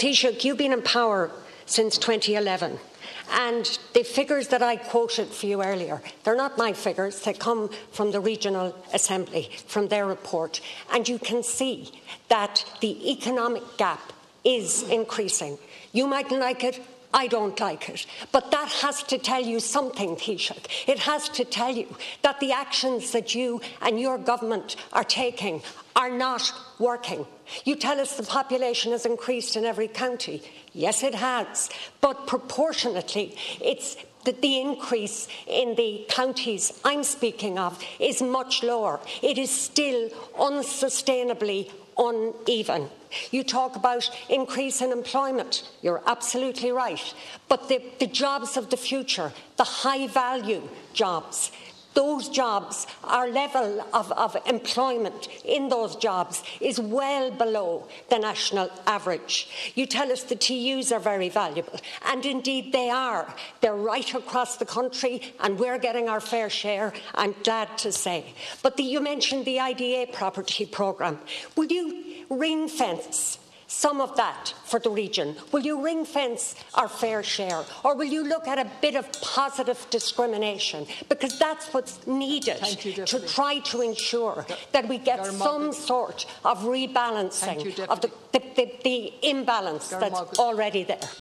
The Dail has heard that GDP per capita in the North West is 17% below the EU average.
South Donegal Deputy Marian Harkin urged Leo Varadkar to deliver a fair share for the region: